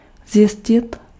Ääntäminen
France (Paris): IPA: [ve.ʁi.fje]